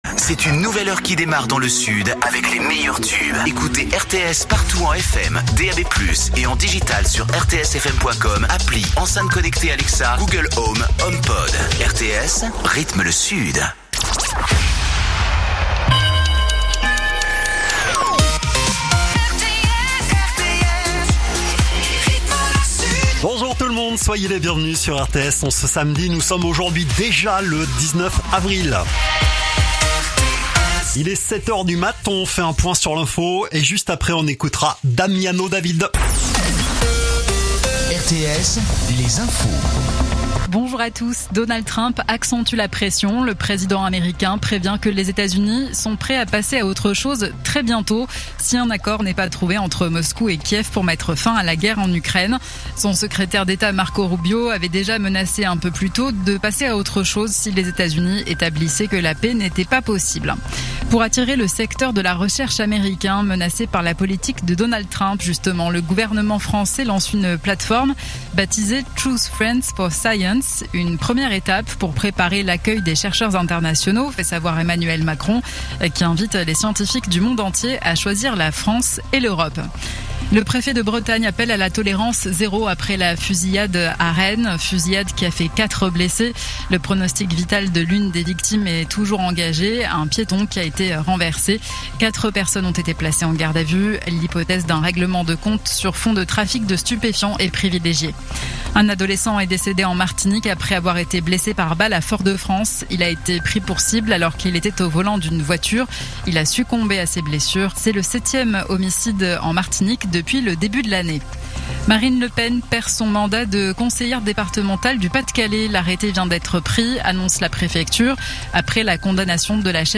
Écoutez les dernières actus de Perpignan en 3 min : faits divers, économie, politique, sport, météo. 7h,7h30,8h,8h30,9h,17h,18h,19h.